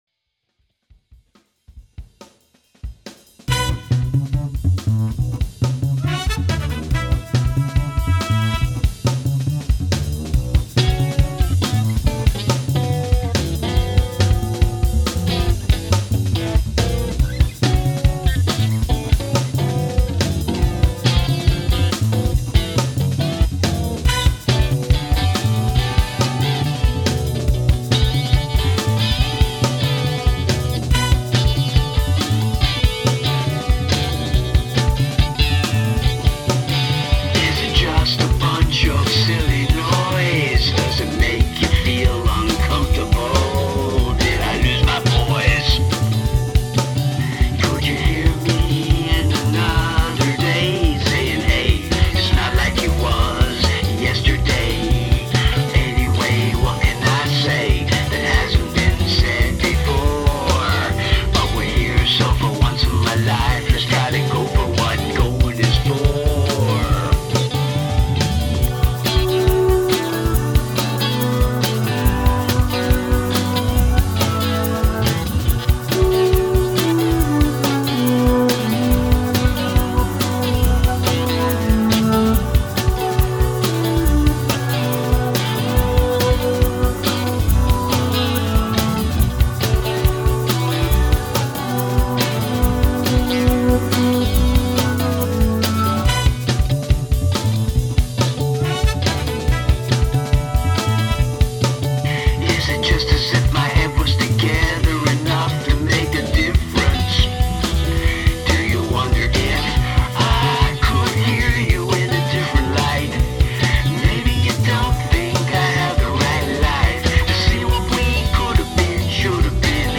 He discovered Garage Band and went to town on this one. Earth Wind and Fire with a spoken word on top. A nice mash-up.